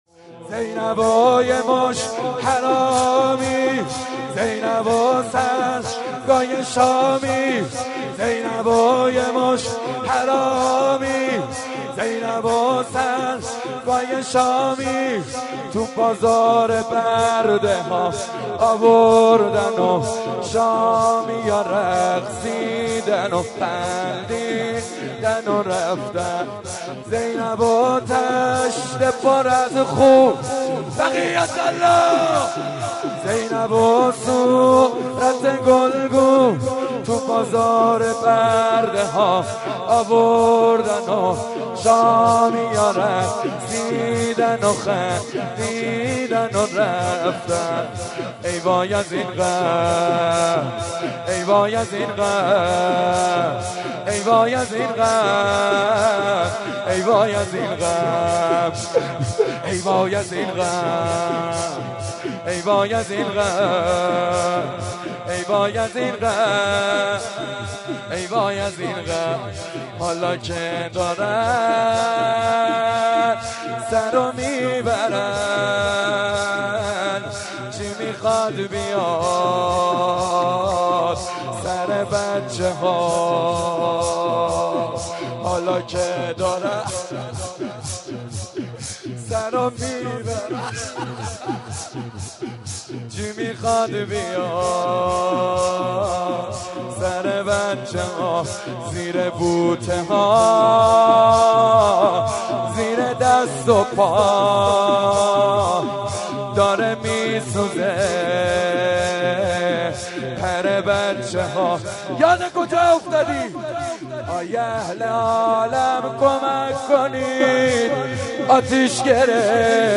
روز اربعین مهدیه امام حسن مجتبی(ع)
روضه